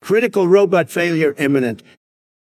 critical-robot-failure.wav